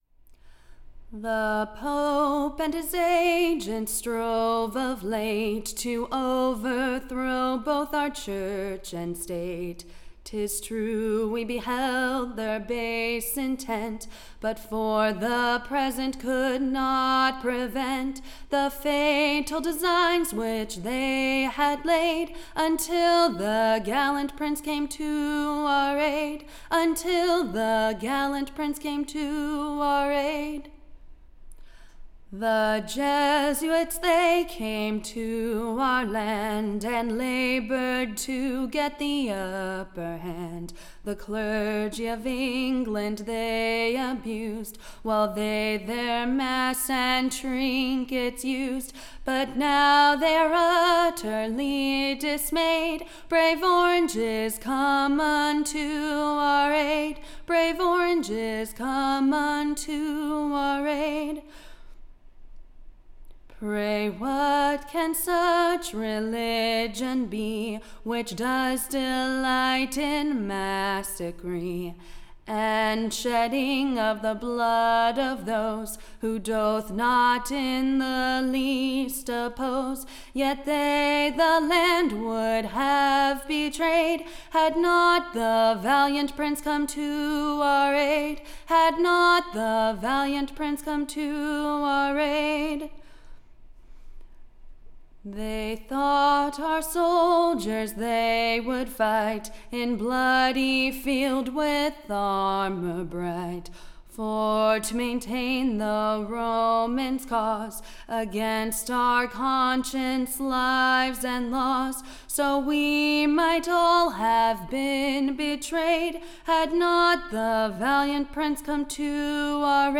Recording Information Ballad Title The Downfall of POPERY; / OR, The Distressed JESUITS in Flight.